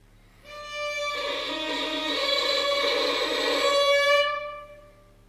Als „Knarzen“ oder Schnarren lässt sich ein Klang bezeichnen, der z.B. durch zu hohen Saitendruck entsteht. Dieser Klang kann leicht erreicht werden, indem man beispielsweise mit einer Kontaktstelle über dem Griffbrett und relativ hohem Saitendruck bei gleichzeitig geringer Bogengeschwindigkeit streicht:
Knarzgeräusch
knarzen.mp3